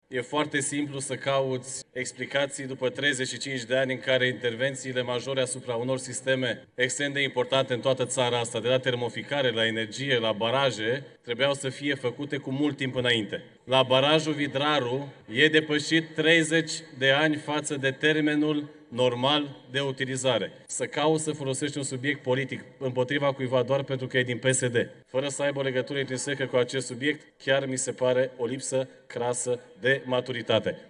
Acesta a fost invitat de grupul parlamentar USR la Ora Guvernului, în contextul crizei apei potabile din Curtea de Argeș și alte câteva localități apropiate.